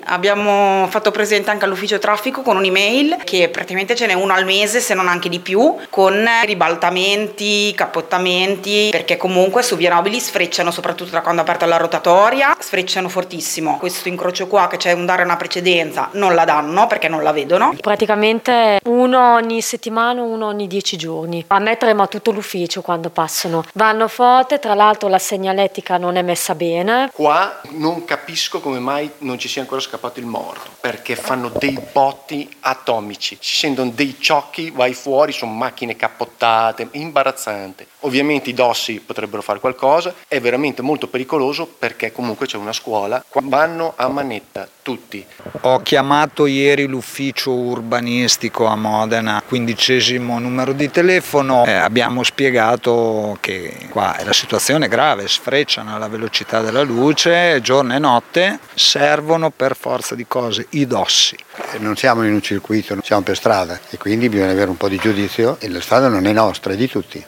Le interviste:
VOX-VIA-NOBILI.mp3